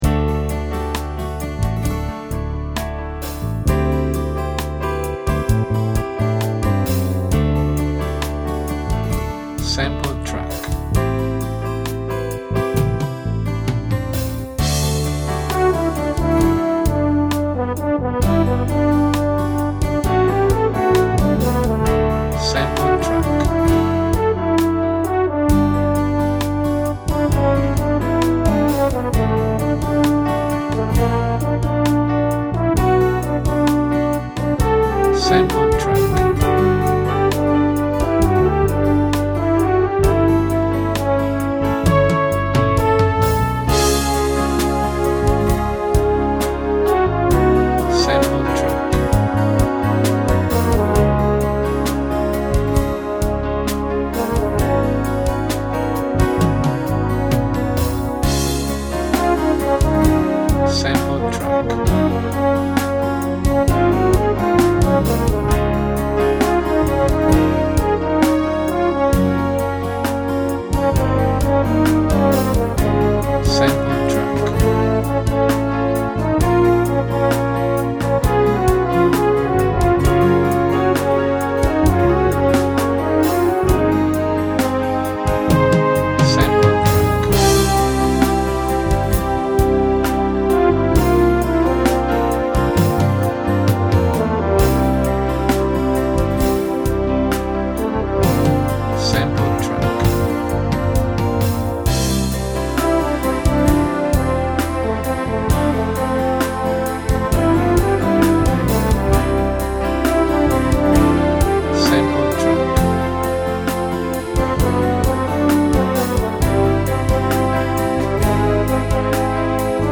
Orchestrale / Epico
Sinfonico e positivo.